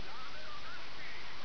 Type: Sound Effect